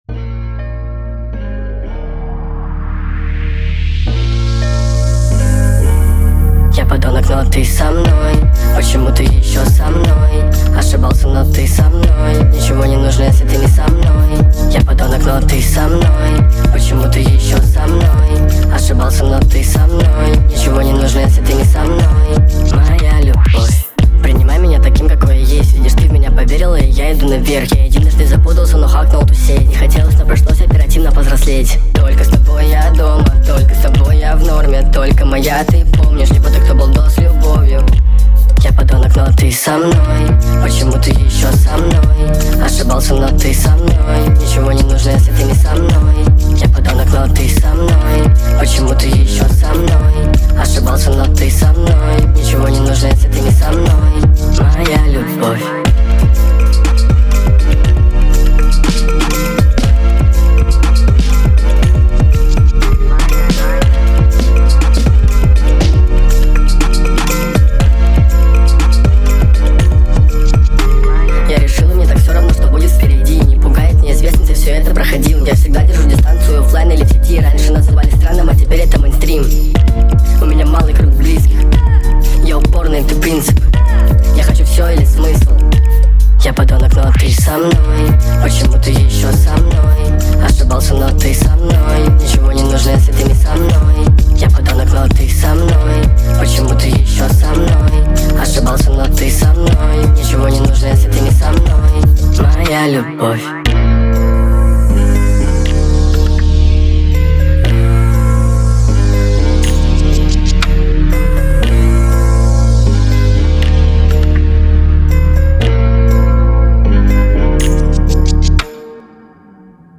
Remixes